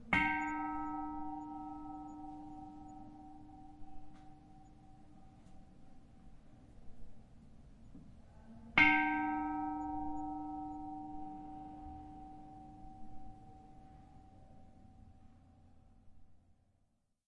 奥兰 " 船铃 7
描述：在ÅlandMarithamof Mariehamn海上博物馆用手（＃1至＃8）击中不同的船铃。
Tag: 叮当 现场记录